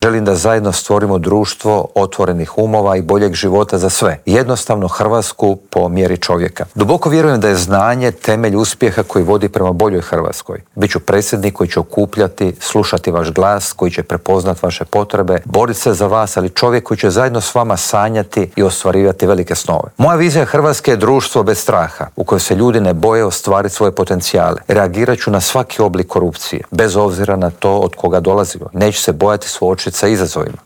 U studiju Media servisa istaknuo je da živimo u vremenima globalne nesigurnosti, ratova, klimatskih promjena i demografskog slabljenja.